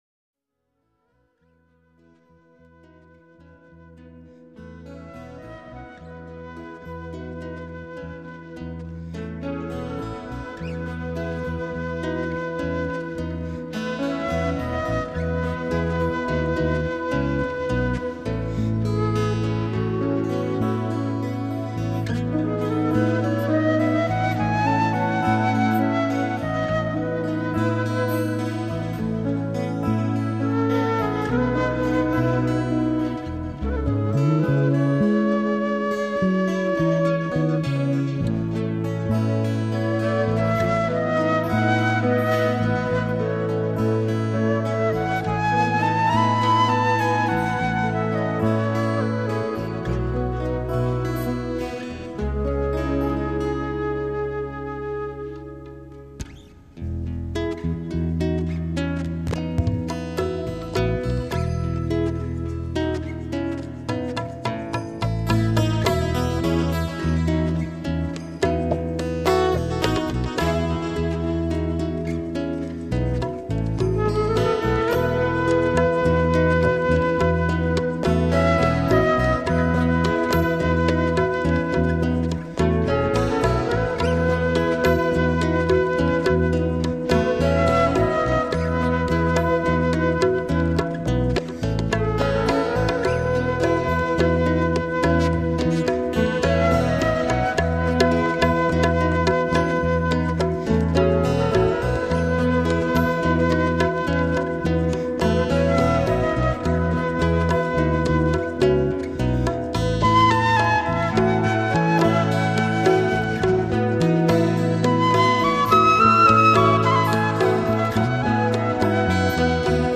关于减压音乐：